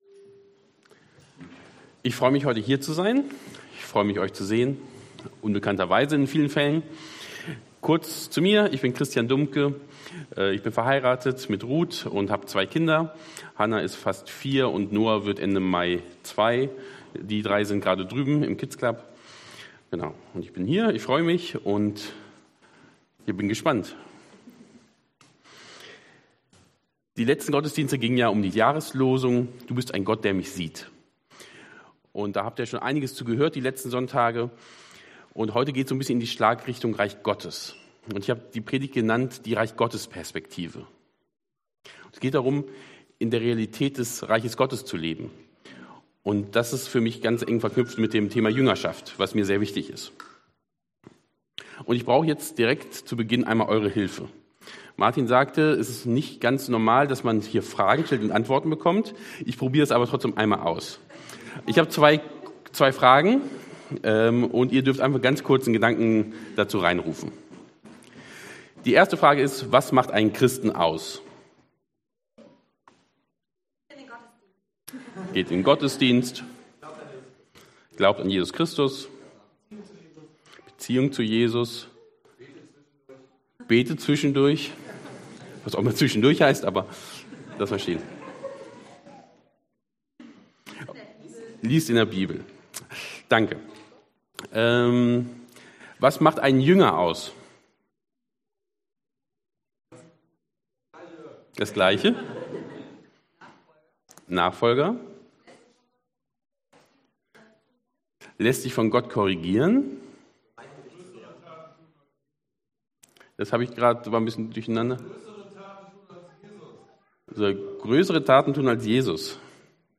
Markus 1,15 Dienstart: Predigt « …und an meinem Leben interessiert ist.